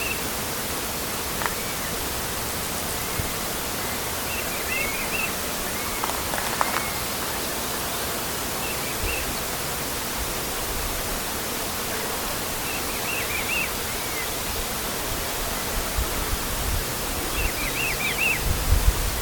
GAVIÃO-PEGA-MACACO
Nome em Inglês: Black Hawk-Eagle
Local: RPPN Corredeiras do Rio Itajaí -  Itaiópolis - SC